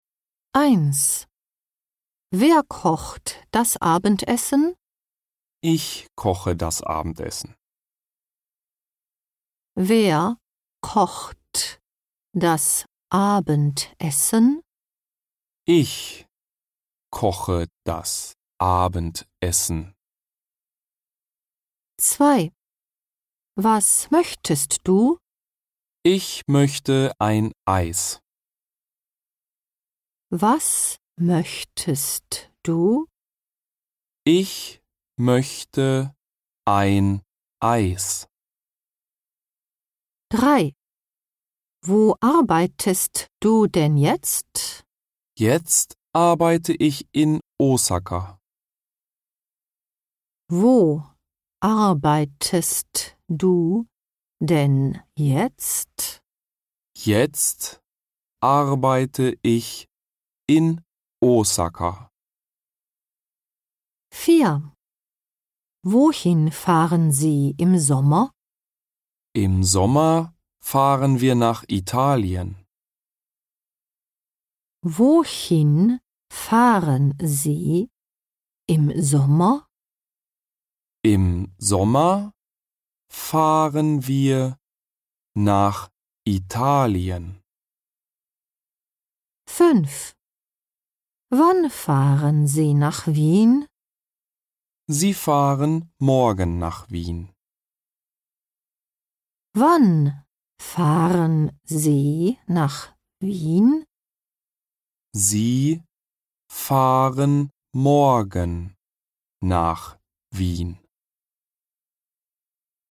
CD収録の音声に加えできるだけ多くの例文を、ネイティブがノーマルスピードとゆっくりスピードで読み上げたものがございます。
18　25頁　対策学習（ノーマル＆ゆっくり）